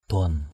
/d̪ʊa:n/ (d.) xí = étendard. dok duan _dK d&N cờ xí = drapeaux.